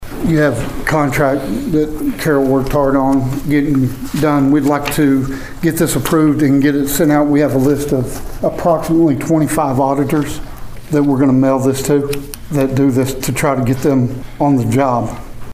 That is why on Tuesday, the council approved a request for proposal to take the first step so that the audit could be completed. Pawhuska City Manager Jerry Eubanks goes into detail